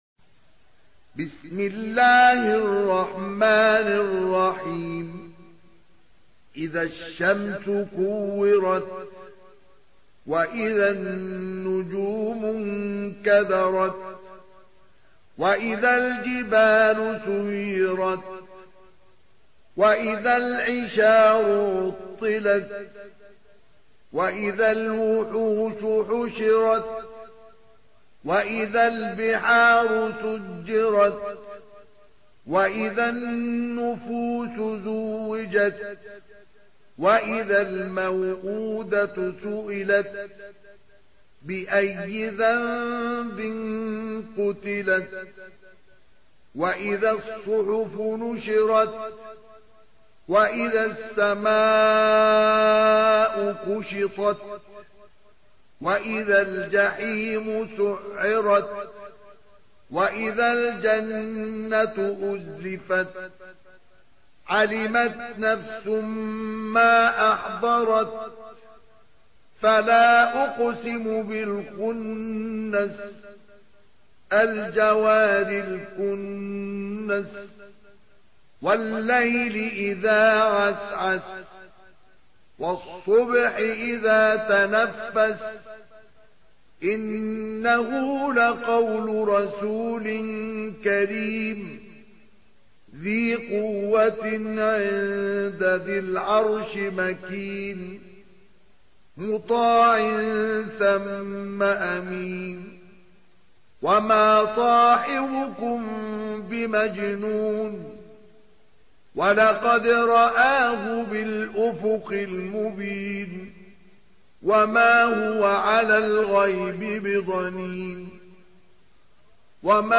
سورة التكوير MP3 بصوت مصطفى إسماعيل برواية حفص
مرتل